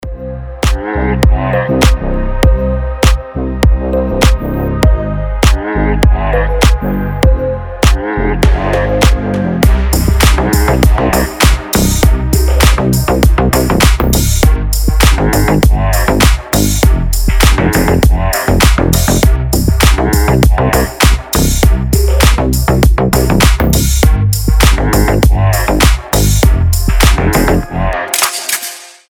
• Качество: 320, Stereo
deep house
атмосферные
басы
космические
G-House
Медленный темп с вкусным размеренным басом